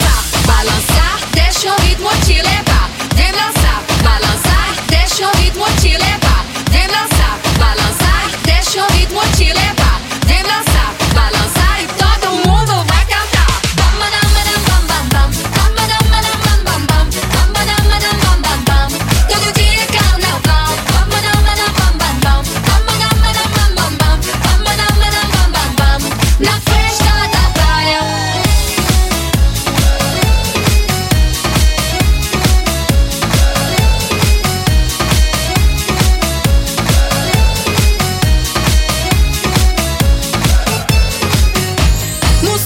latin
Genere: latin pop, latin house, latin tribal, reggaeton